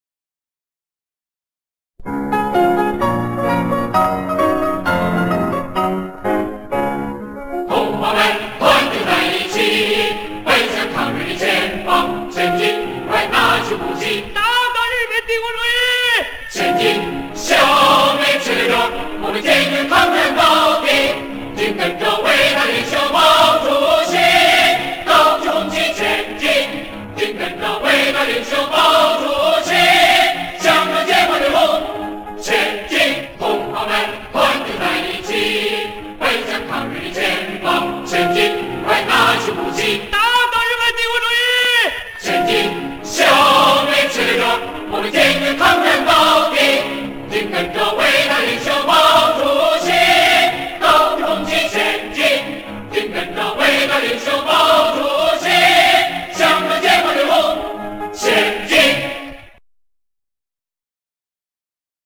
[5/9/2009]革命历史歌曲：前进歌 （打倒日本帝国主义！！！）